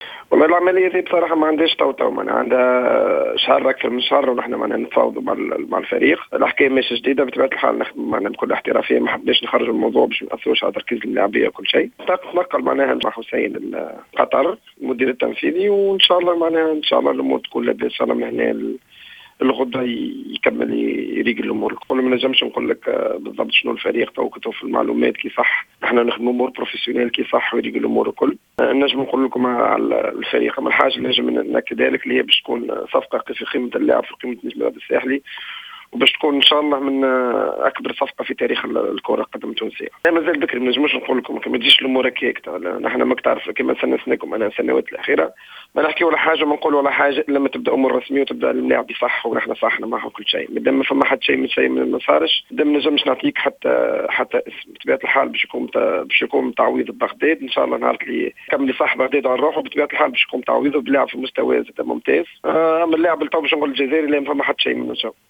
Le directeur sportif de l’ESS, Zied Jaziri a indiqué ce mercredi 29 avril 2015 dans une intervention sur les ondes de Jawhara FM, que l’attaquant algérien de l’équipe, Baghdad Bounjah, évoluera bientôt dans une équipe qatarienne.